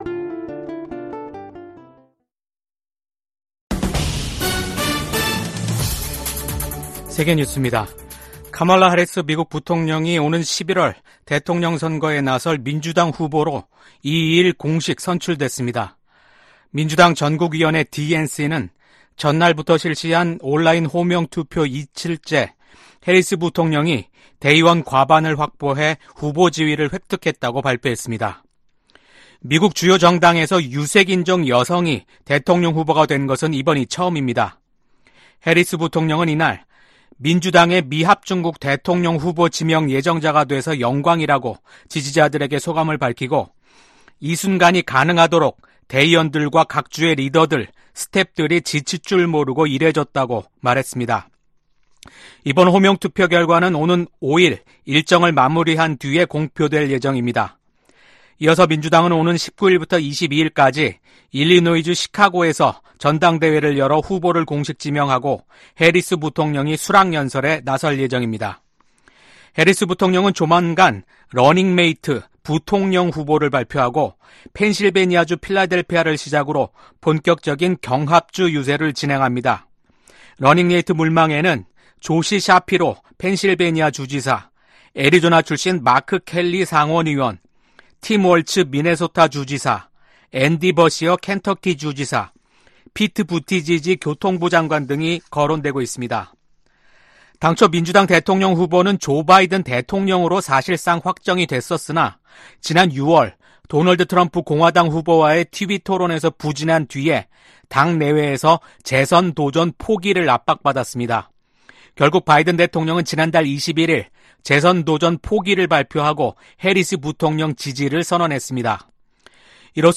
VOA 한국어 아침 뉴스 프로그램 '워싱턴 뉴스 광장' 2024년 8월 3일 방송입니다. 중국, 러시아, 북한이 계속 핵전력을 증대한다면 미국은 핵 태세와 규모를 조정할 수도 있다고 미 국방부 고위관리가 밝혔습니다. 국무부는 북한의 대남 오물 풍선 살포를 무모하고 불안정한 ‘도발’로 규정했습니다. 한국 정부가 집중호우로 심각한 피해를 입은 북한에 인도적 지원을 전격 제안했습니다.